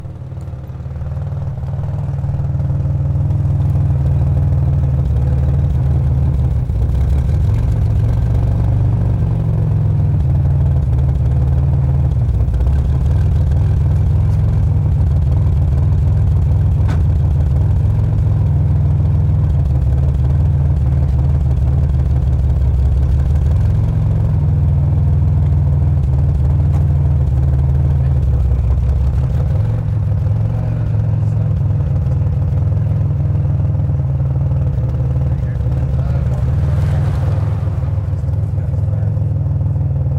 随机 " 汽车性能汽车隆隆声发动机怠速关闭bassy
描述：汽车性能汽车发动机怠速关闭bassy.flac
Tag: 性能 汽车 空闲 rumbly 汽车 发动机